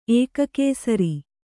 ♪ ēkakēsari